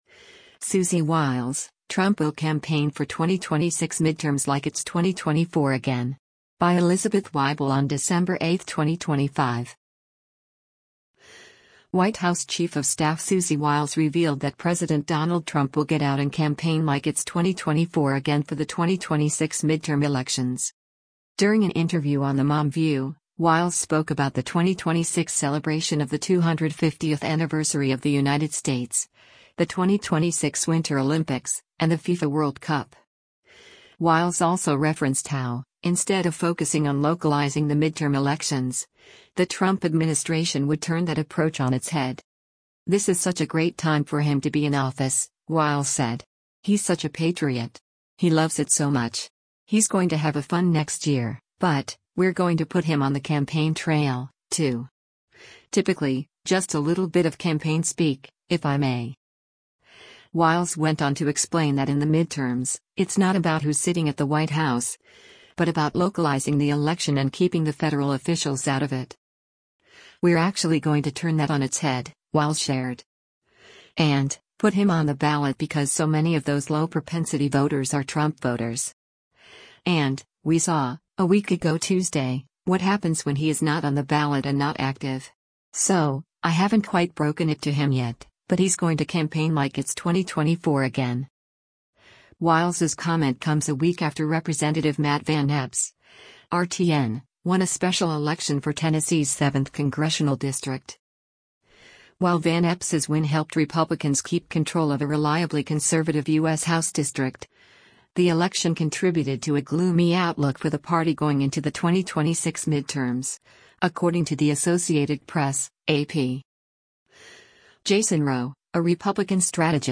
During an interview on The Mom View, Wiles spoke about the 2026 celebration of the 250th anniversary of the United States, the 2026 Winter Olympics, and the FIFA World Cup.